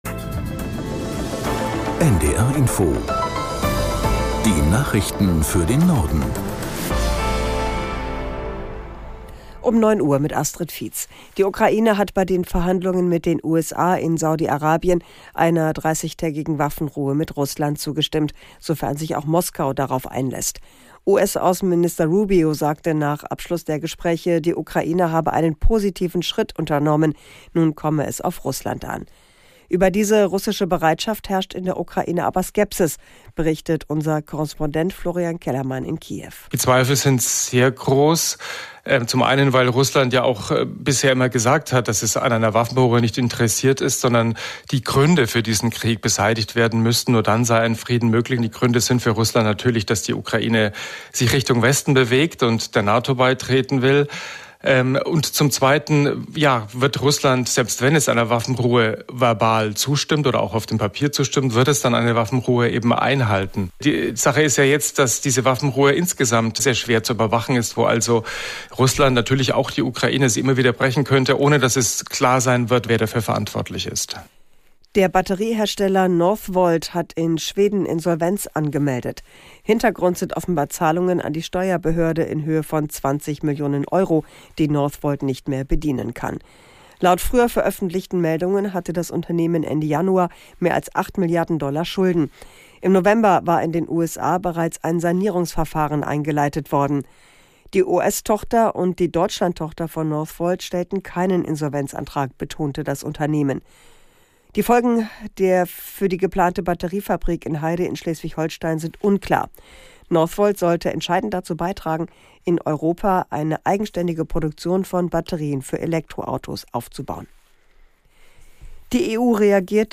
Nachrichten - 12.03.2025